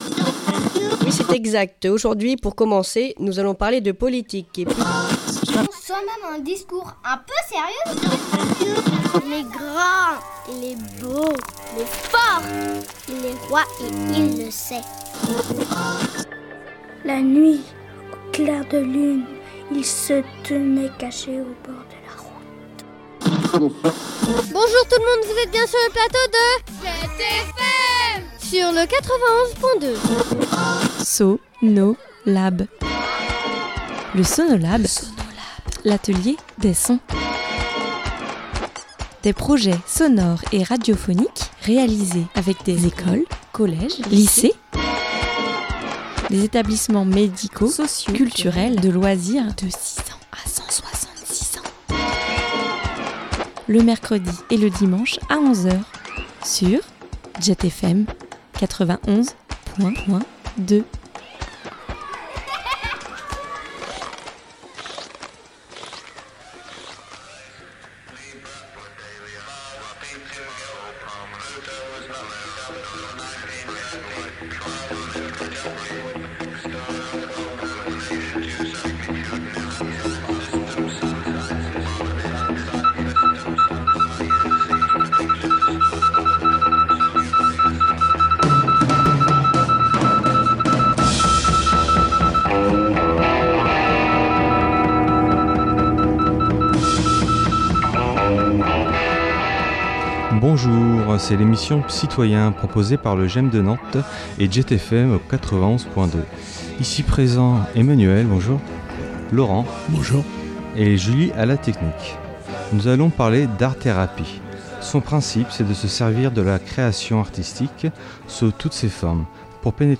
Un atelier radio en collaboration avec Jet a lieu tous les quinze jours, pour que les adhérents qui le souhaitent préparent cette émission. L’idée est d’apporter un regard et une parole différente à la maladie psychique.